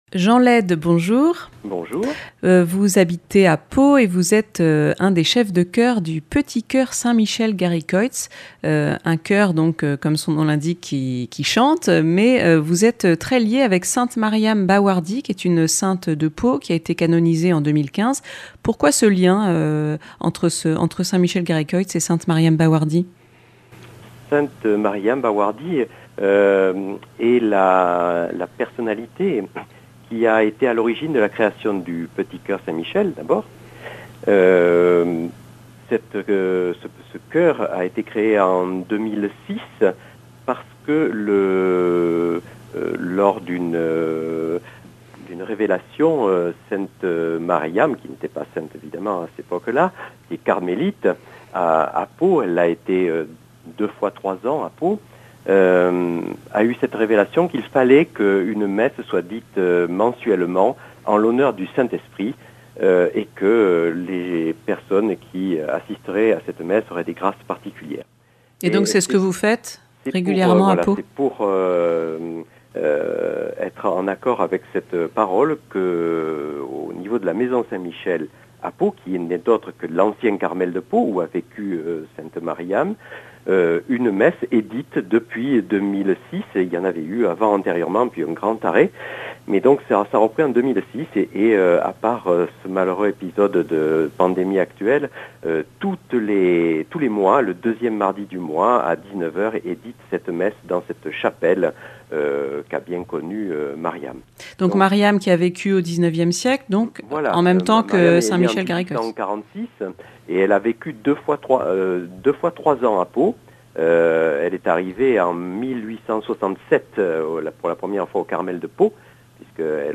Le petit choeur de St Michel Garicoïts chante Mariam Baouardy